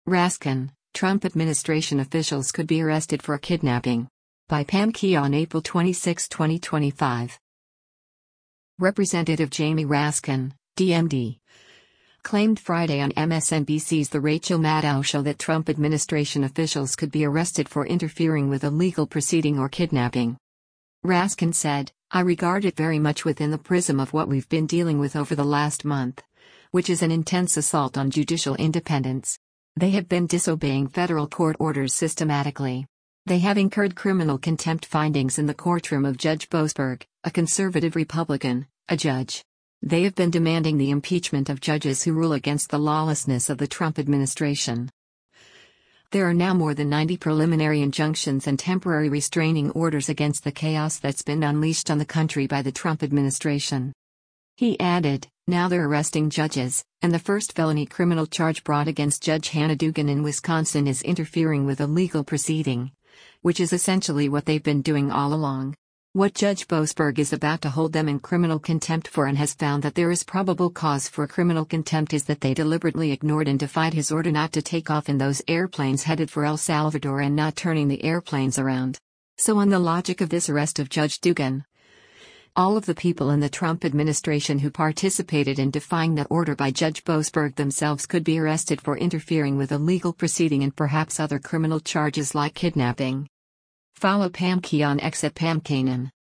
Representative Jamie Raskin (D-MD) claimed Friday on MSNBC’s “The Rachel Maddow Show” that Trump administration officials could be arrested for “interfering with a legal proceeding” or “kidnapping.”